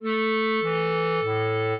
clarinet
minuet7-2.wav